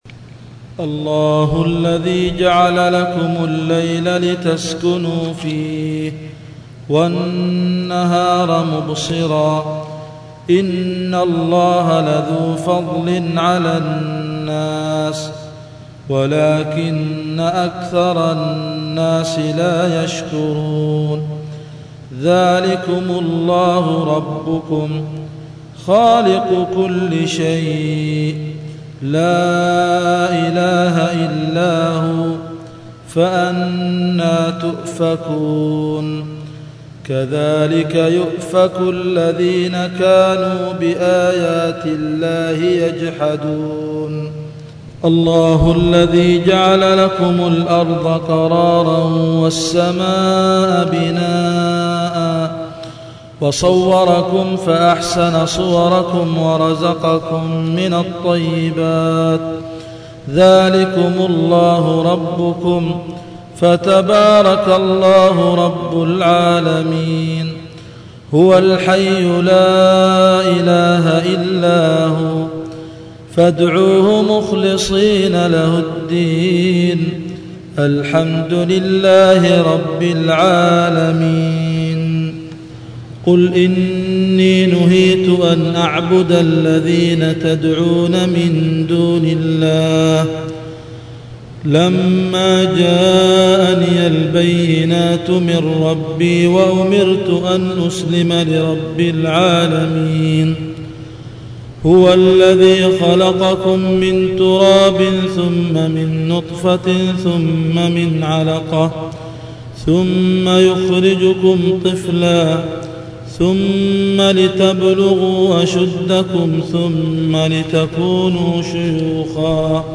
تلاوات